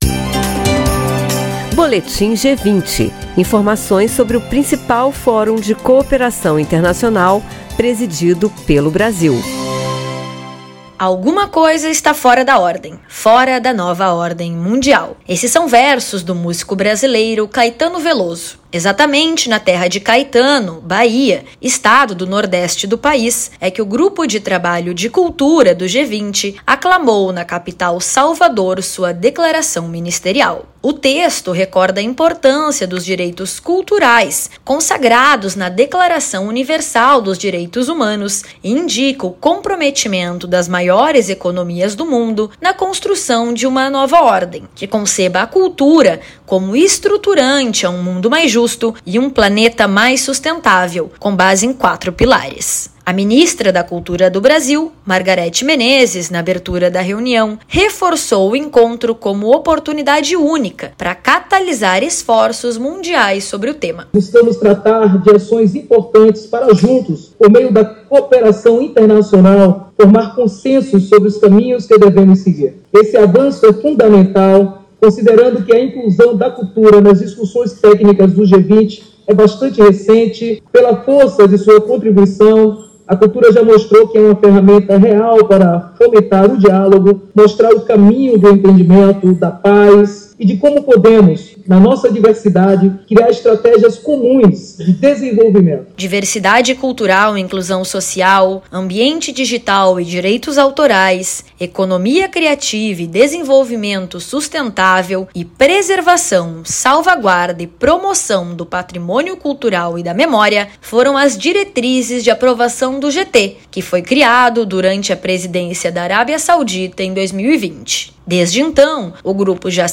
A pesquisa foi apresentada em Salvador (BA) no Seminário Internacional de Cultura e Mudança do Clima, promovido pelo Grupo de Trabalho (GT) de Cultura. O intuito é informar interessados na temática sobre a importância de criar e promover políticas públicas culturais focadas em soluções para a crise do clima, incluindo a preservação de bens culturais e educação climática de agentes públicos. Ouça a reportagem e saiba mais.